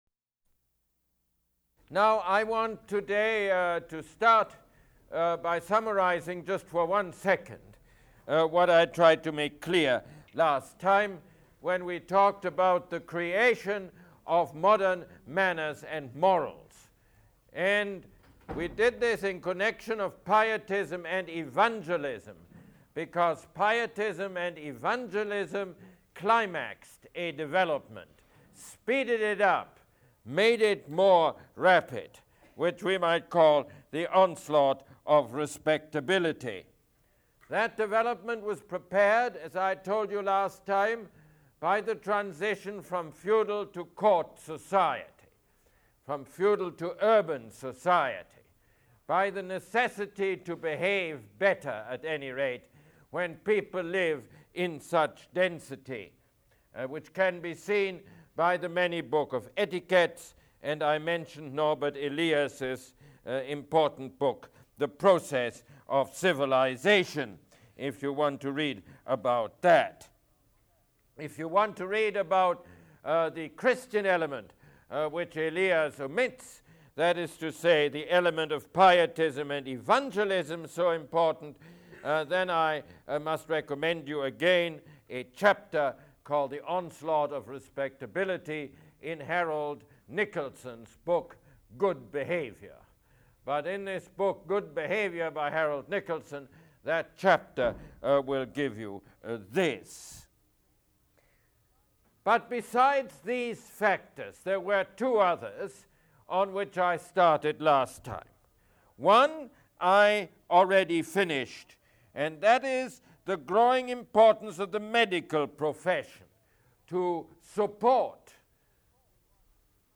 Mosse Lecture #12